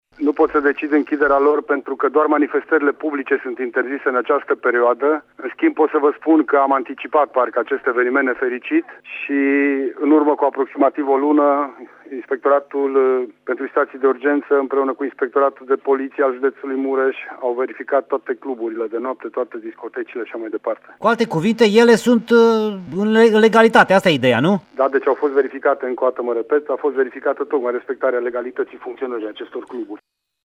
În Mureș, cluburile au fost verificate cu o lună în urmă, a declarat, pentru Radio Tîrgu-Mureș, prefectul județului Mureș, Lucian Goga: